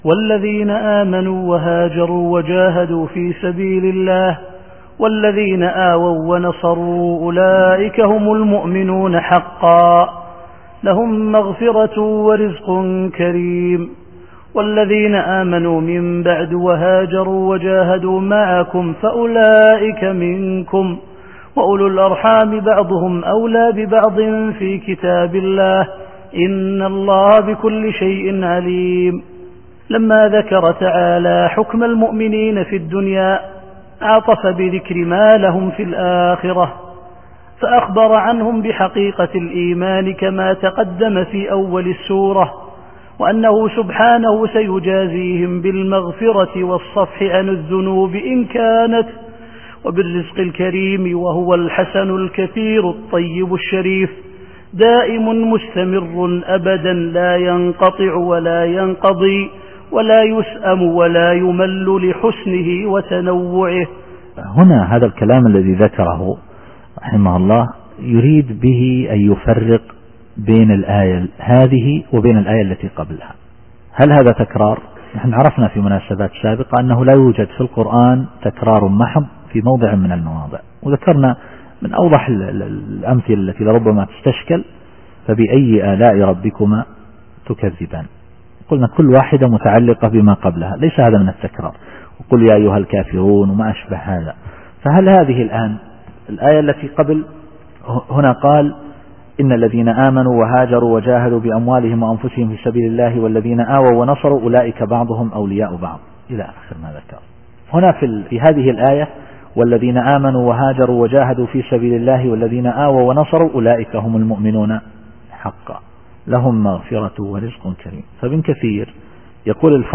التفسير الصوتي [الأنفال / 74]